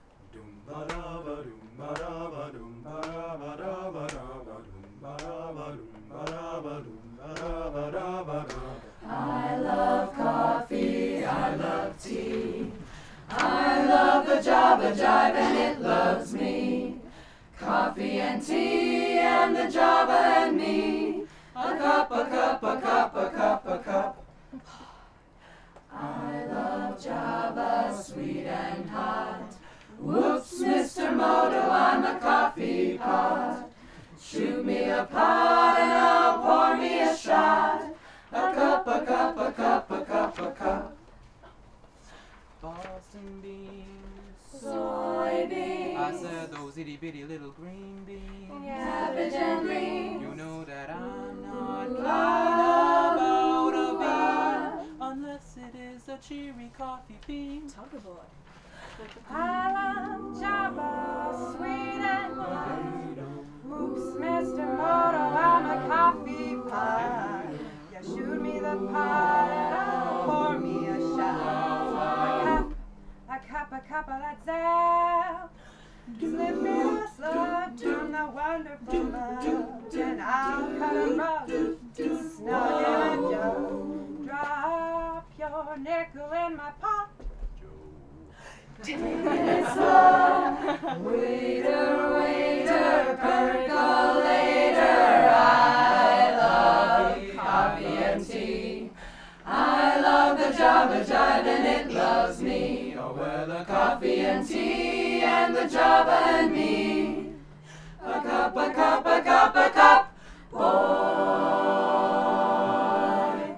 2:00 PM on August 3, 2014, "On the Deck"
Madrigals